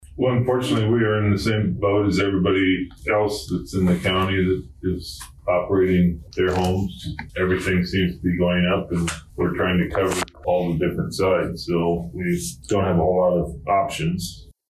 That was Humboldt County Supervisor Bruce Reimers, who says it was a difficult balance to find the right figures.